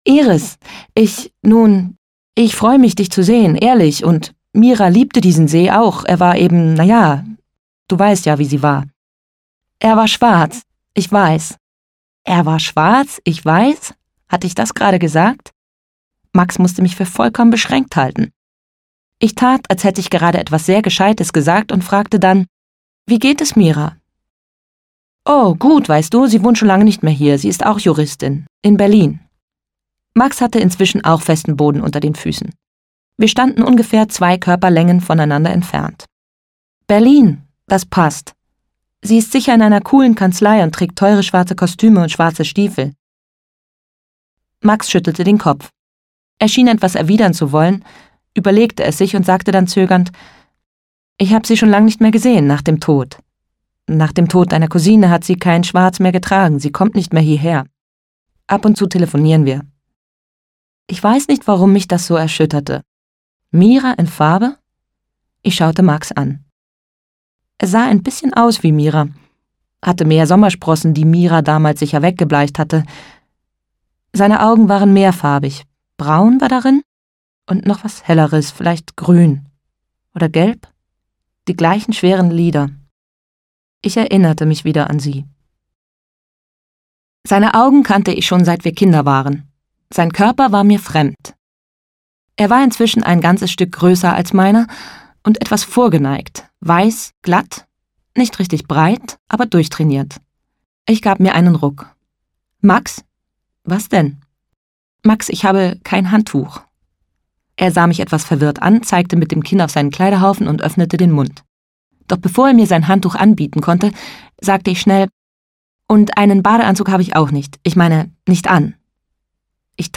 Maren Eggert (Sprecher)
Sie handeln von Freundschaft, Liebe, Altern und dem rätselhaften Tod ihrer Kusine Rosmarie. Bewegend und herrlich komisch ist Schauspielerin Maren Eggert als Iris zu hören, die sich an drei Generationen ihrer Familie erinnert und gleichzeitig ihre eigene Geschichte spinnt.